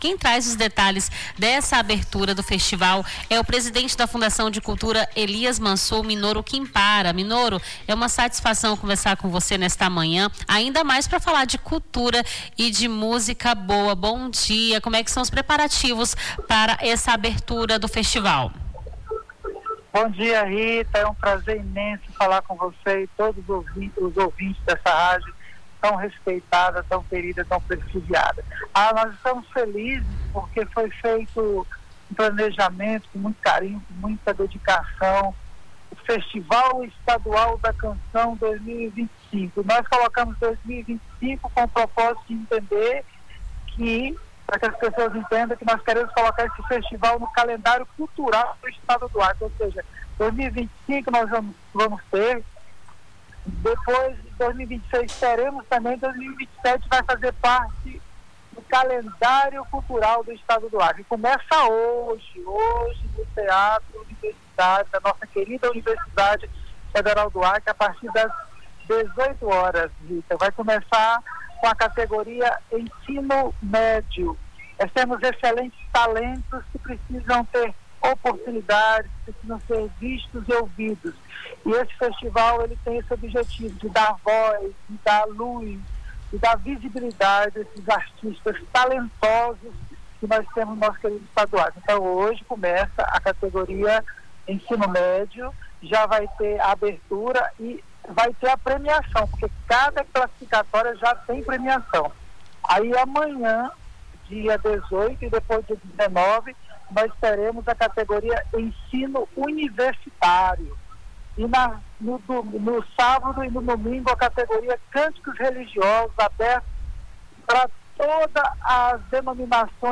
O Jornal da Manhã conversou com Minoru Kinpara, presidente da Fundação de Cultura Elias Mansour, sobre a abertura do Festival Estadual da Canção 2025, que começa nesta quarta-feira (17), às 19h, no Teatro Universitário da Ufac.